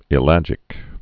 (ĭ-lăjĭk)